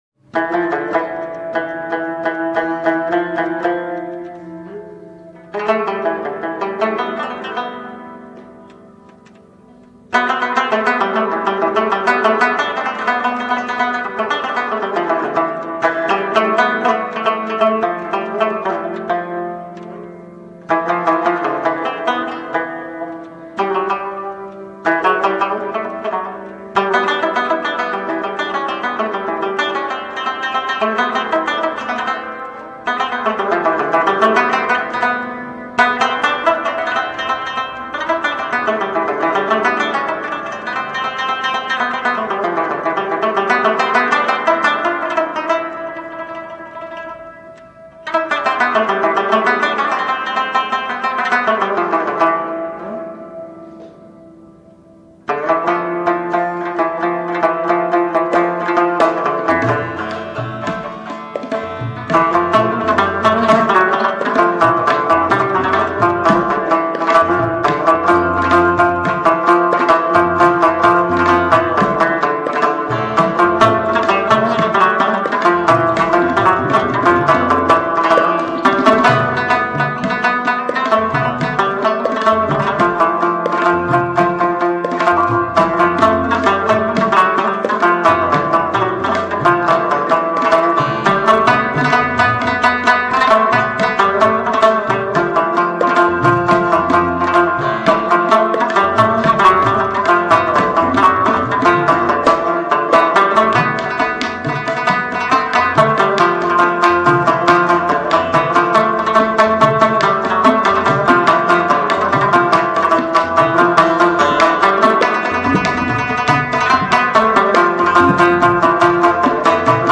رباب
این ساز از سازهای کهن ایرانی و در دسته سازهای زهی زخمه ای است که نواختن آن بیشتر در مناطق شرق و جنوب شرق ایران و کشورهای اطراف رواج دارد.
ساز رباب دارای 6 سیم یا 3 سیم جفتی با جنس نایلونی است و وسعت صدای آن حدود یک و نیم اکتاو است.
rabab.mp3